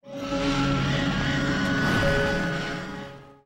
File:Giant centipede roar.mp3
Giant_centipede_roar.mp3